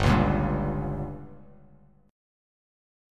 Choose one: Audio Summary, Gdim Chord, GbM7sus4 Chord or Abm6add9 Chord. Gdim Chord